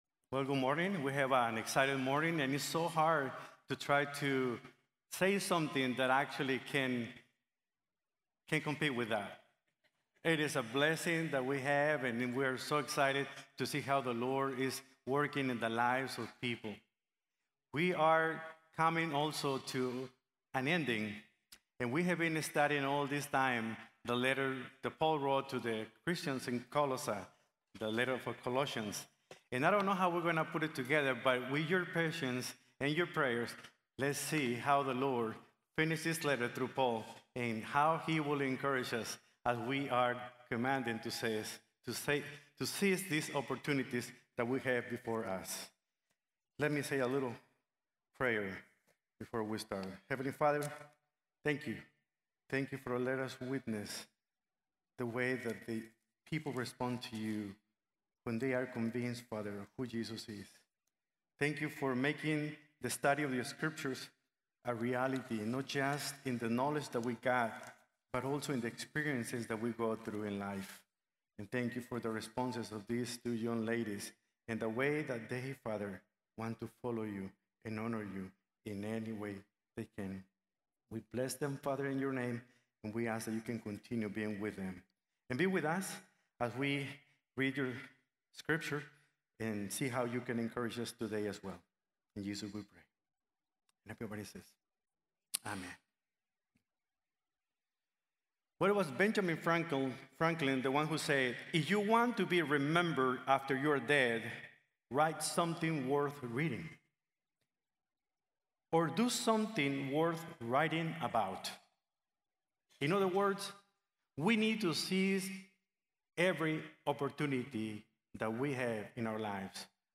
Seizing Opportunities | Sermon | Grace Bible Church